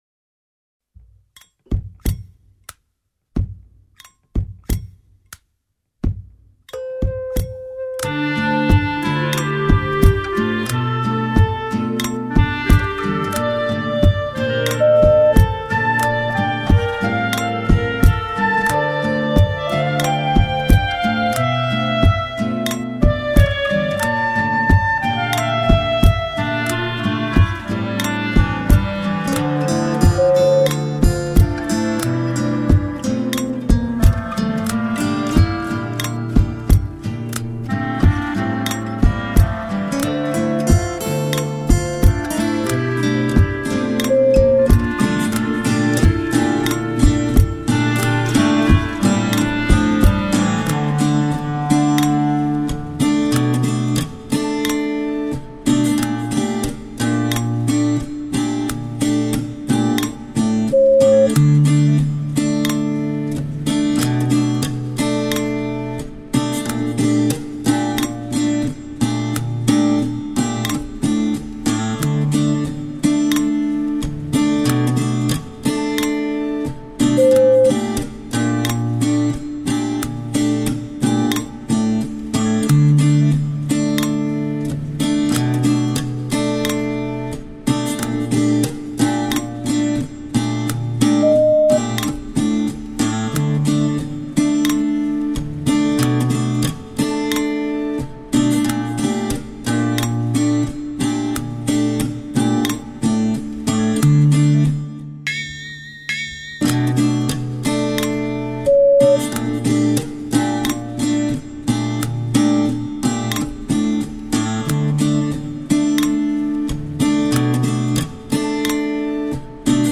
Собственно минусовка..